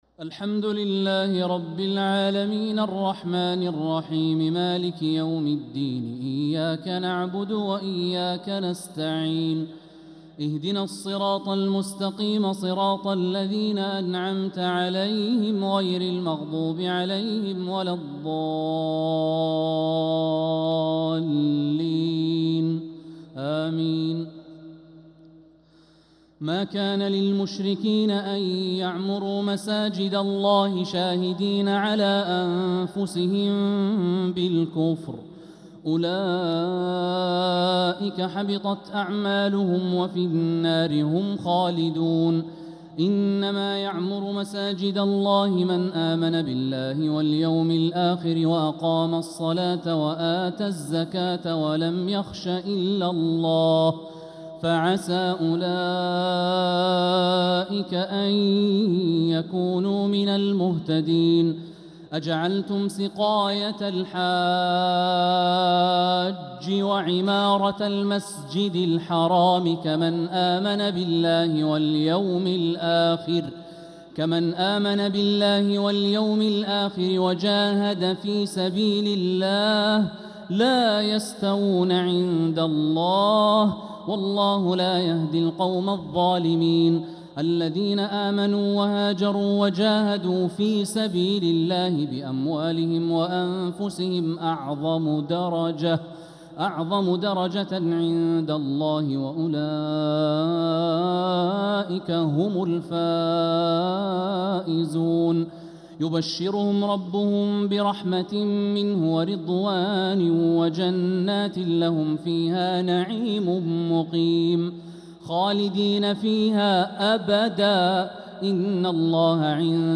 تراويح ليلة 13 رمضان 1446هـ من سورة التوبة (17-39) | Taraweeh 13th niqht Surat At-Tawba 1446H > تراويح الحرم المكي عام 1446 🕋 > التراويح - تلاوات الحرمين